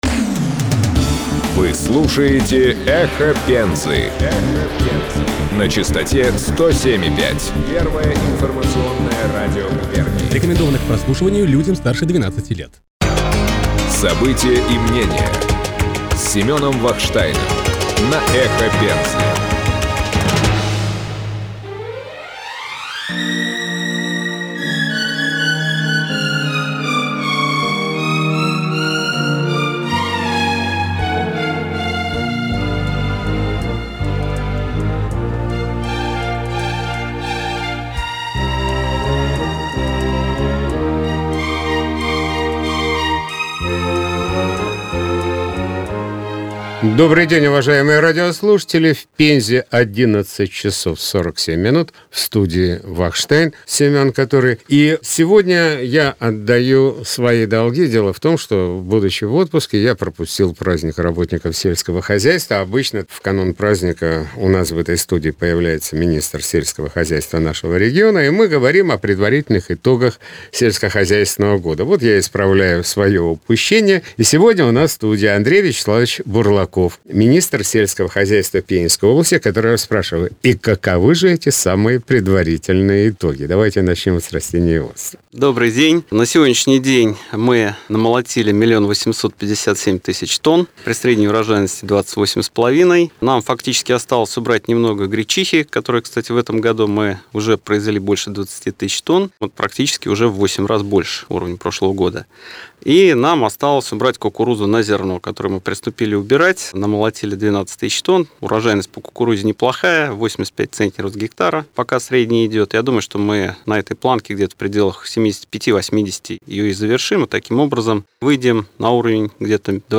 Министр селького хозяства Пензенской области А.Бурлаков дал интервью радиоканалу «Эхо Пензы».